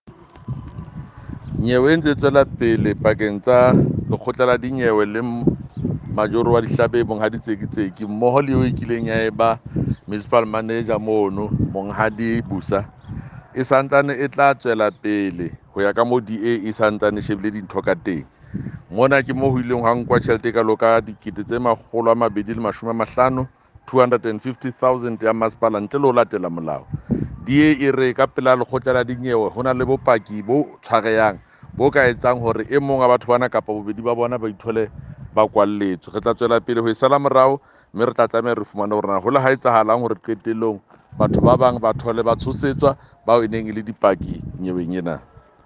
Sesotho by Jafta Mokoena MPL.
Sotho-voice-Jafta.mp3